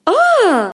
Musiky Bass Free Samples: Voz